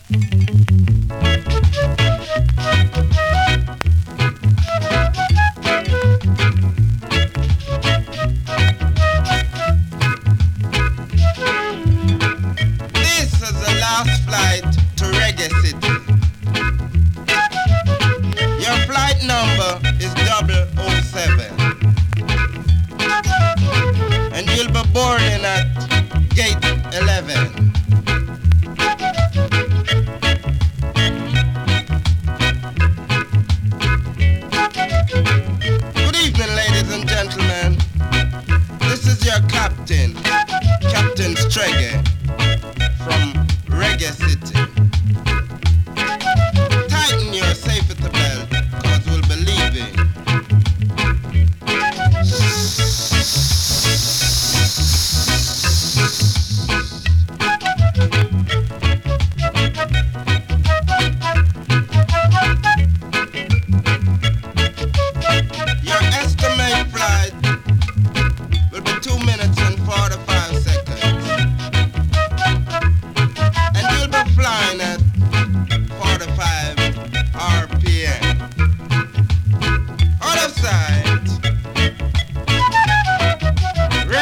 MC&FLUTE TAKE!!
スリキズ、ノイズ比較的少なめで